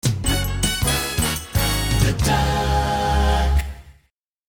The jingles